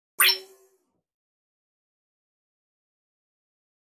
دانلود آهنگ ربات 5 از افکت صوتی اشیاء
دانلود صدای ربات 5 از ساعد نیوز با لینک مستقیم و کیفیت بالا
جلوه های صوتی